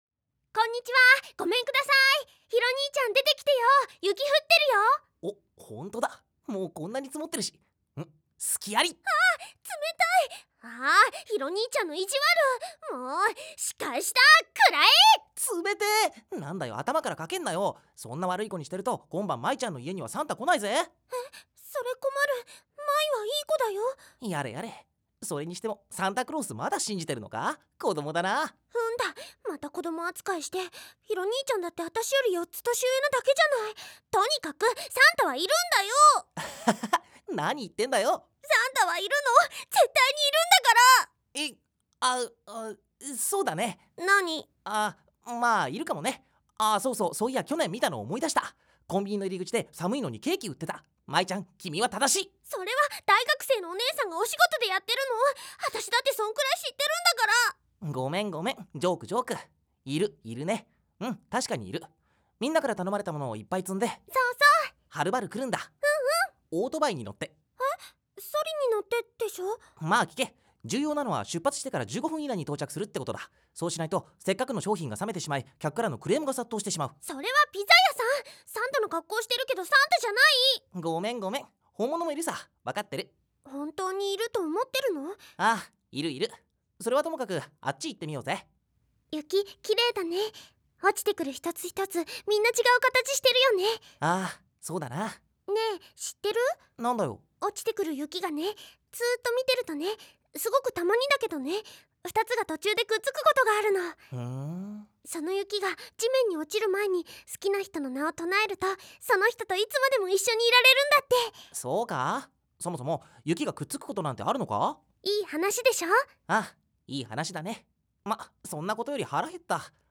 4. 声優によるオリジナル原稿の読み上げ（台詞を連結してストーリーにしたもの）
男性：VM00
女性：VF00
11.31 ［モーラ/秒］
絨毯敷，カーテン有．